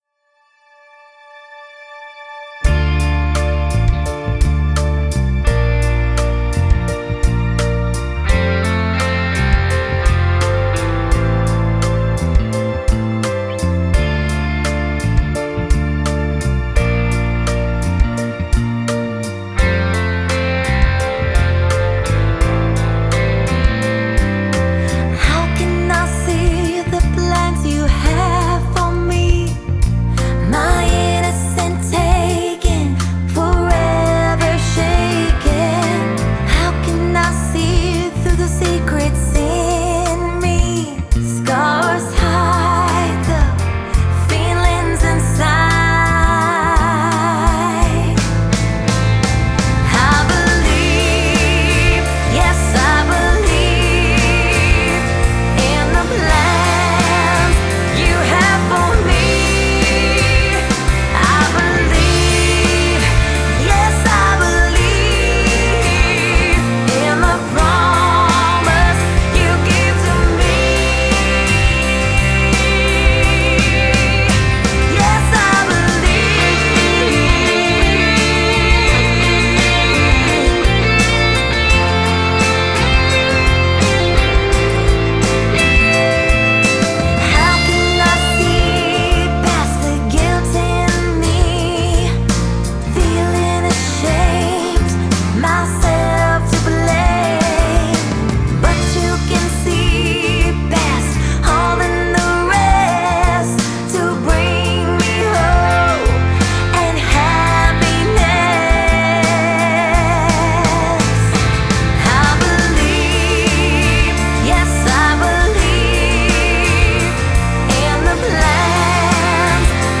lead vocal and BGVs
recorded fantastic rhythm and lead guitar parts! I programmed/played the drums, bass, piano, and pad, all from virtual instruments.. no loops! I mixed and mastered the song.